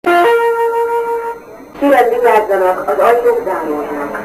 Departure Procedure and Sounds
In refurbished trains there's a female voice: "[Dong-ding] Kerem vigyazzanak, az ajtok zarodnak" (meaning the same,